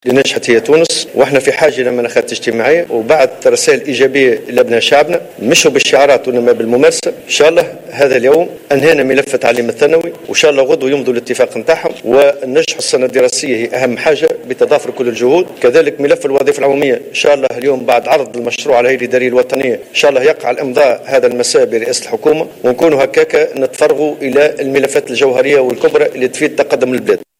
وأضاف الطبوبي في تصريح إعلامي عقب جلسة تفاوضية في وزارة التربية صباح اليوم، أنه سيقع بعد ظهر الغد الجمعة، إمضاء اتفاق سينهي أزمة التعليم الثانوي.